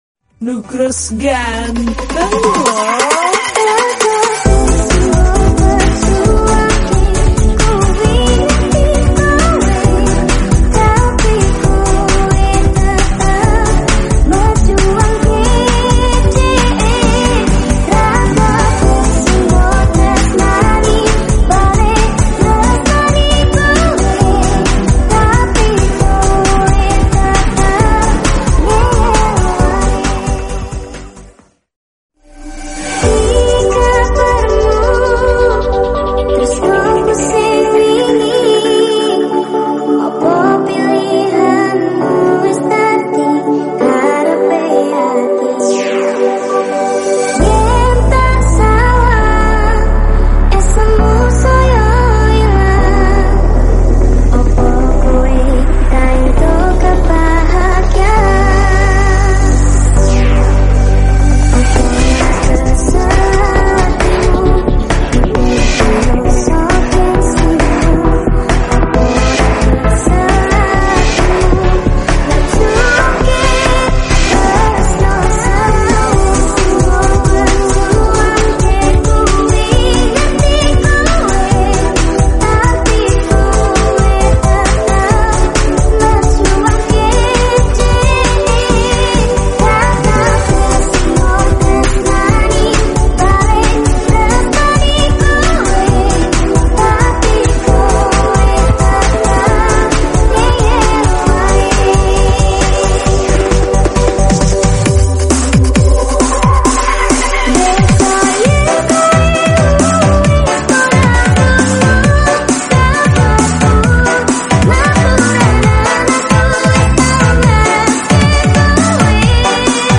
Slow Bass Breakbeat Mengsantuy